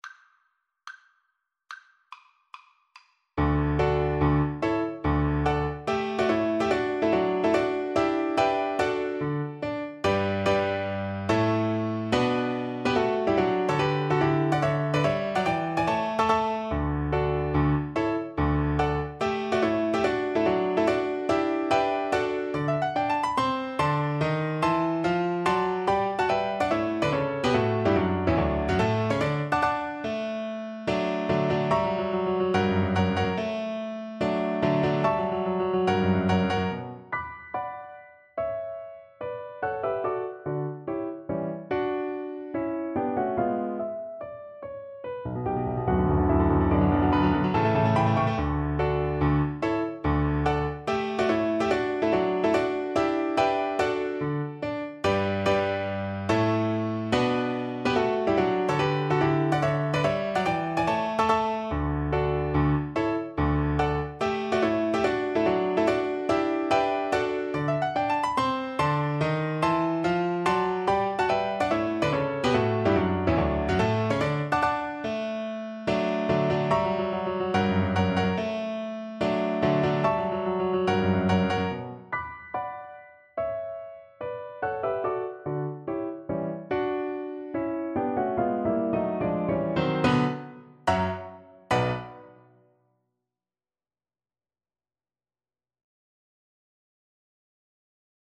4/4 (View more 4/4 Music)
Allegro non troppo (=72) (View more music marked Allegro)
Classical (View more Classical Viola Music)